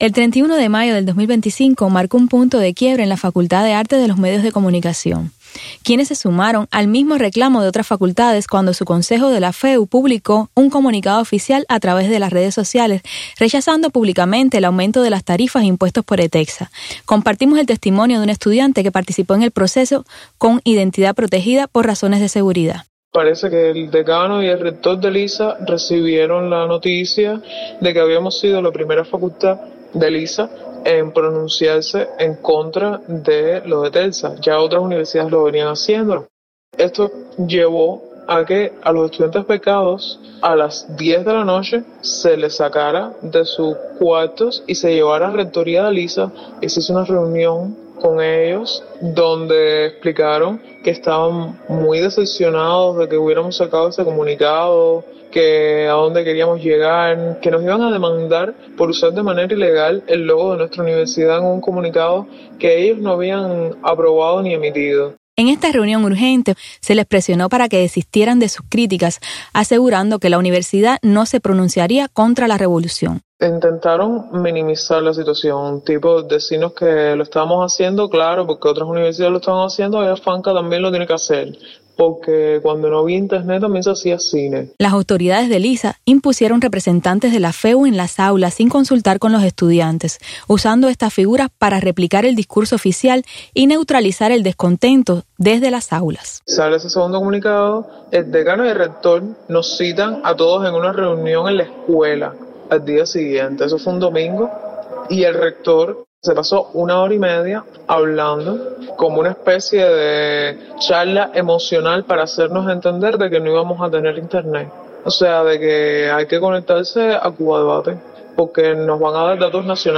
Testimonio de un estudiante del ISA sobre protesta contra ETECSA